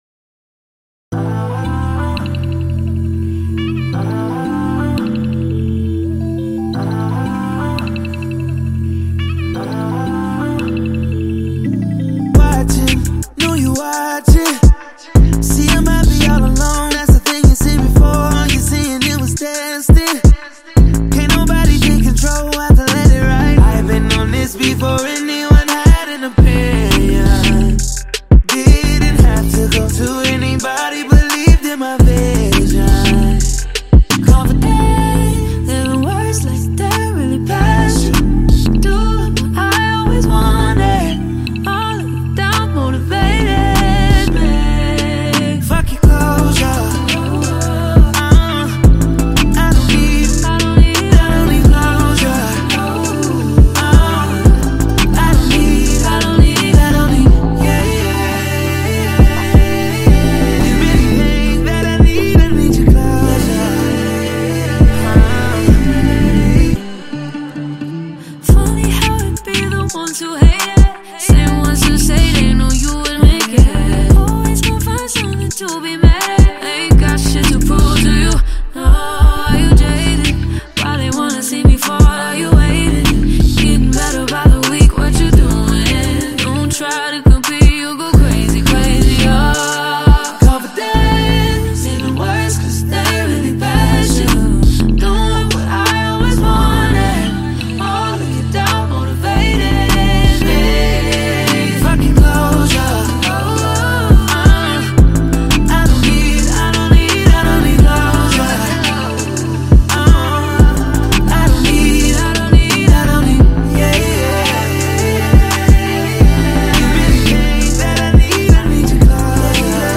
is a smooth, R&B track